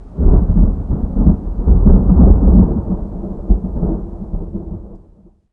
thunder11.ogg